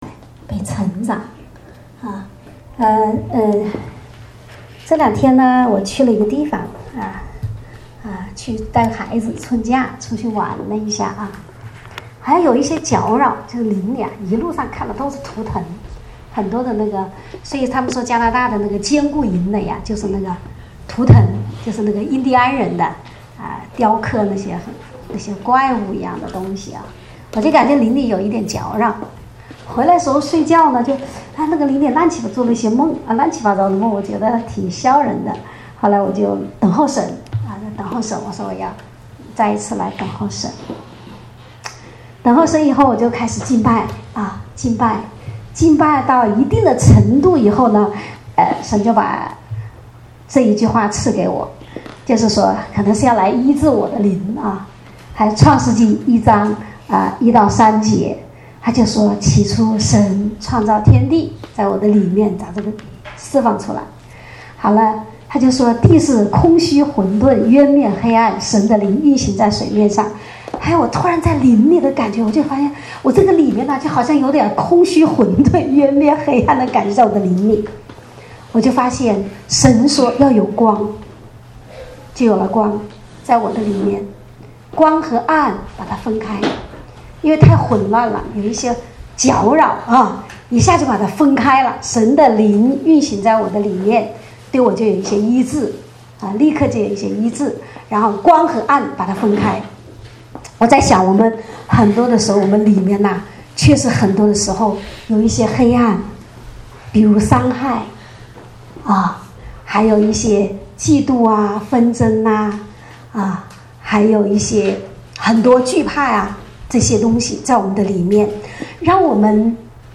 正在播放：--主日恩膏聚会（2015-03-15）